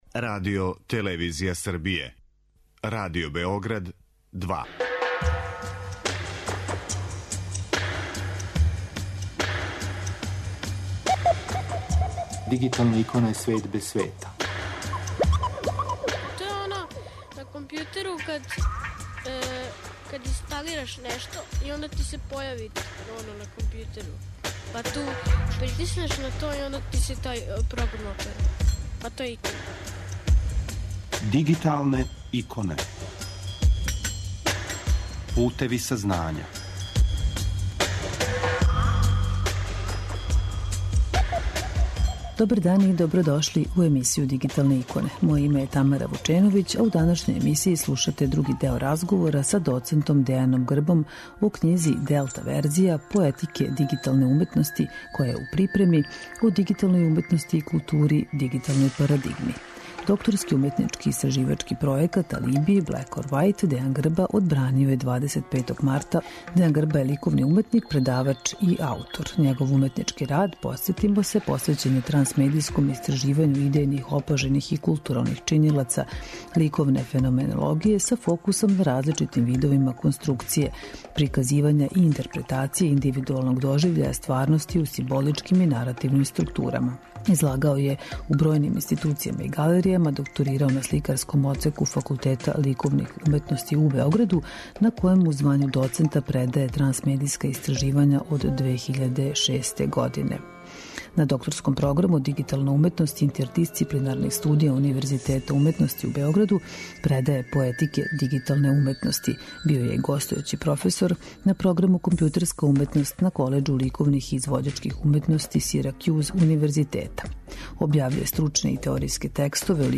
Емисија Дигиталне иконе покренута je 2002. године, а емитује се сваког уторка на таласима Радио Београда 2 од 9 до 10 сати.